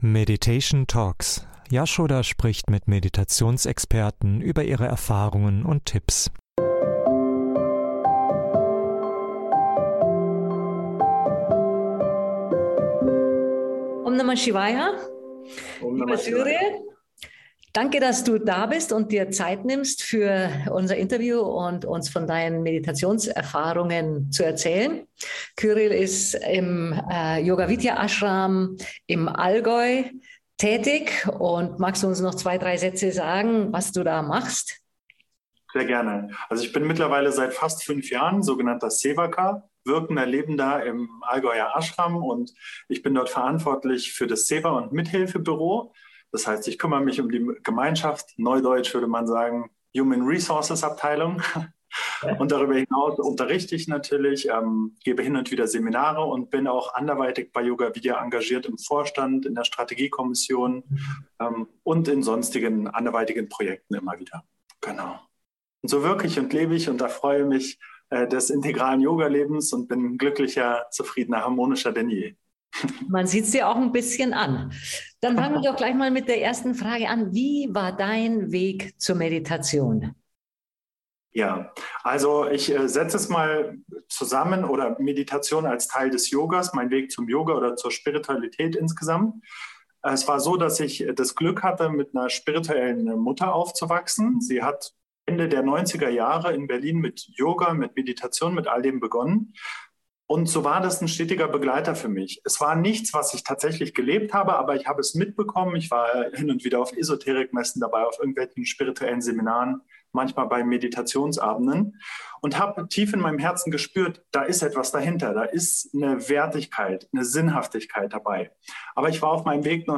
So unterstützen dich die Interviews bei der Vertiefung deiner eigenen Meditationspraxis.